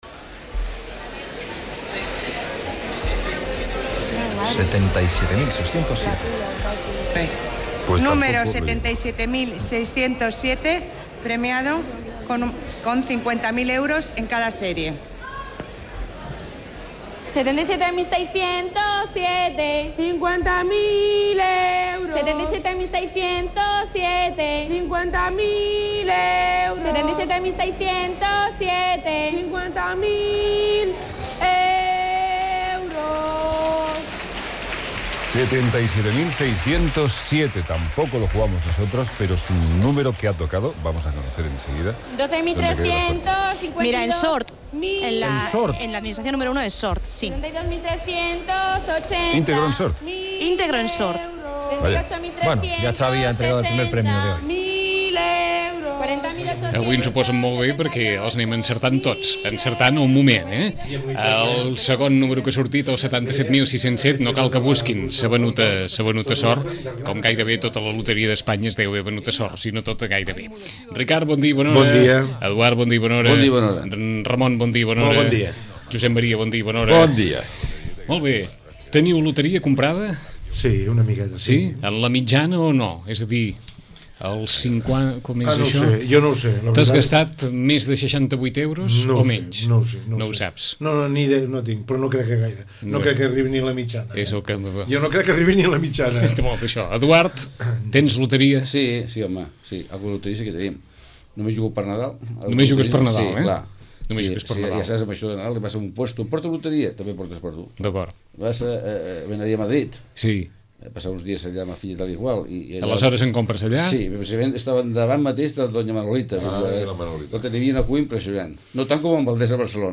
Tertúlia